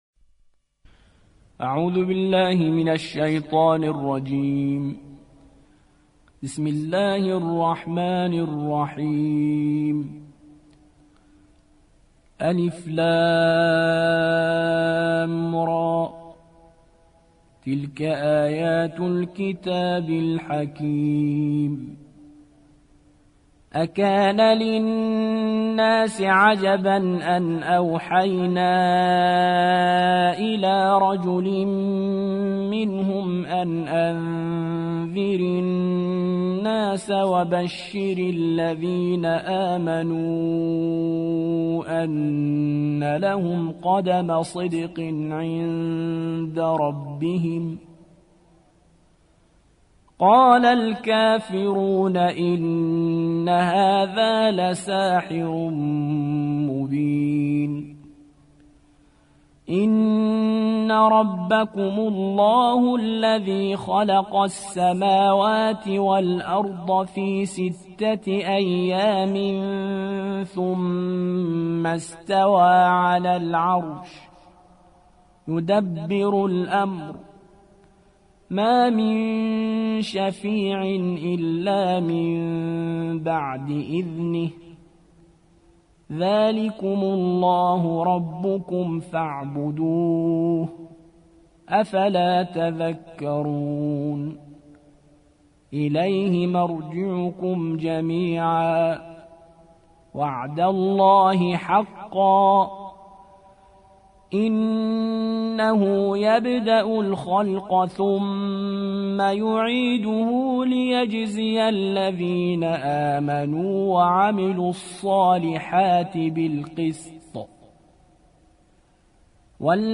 10. سورة يونس / القارئ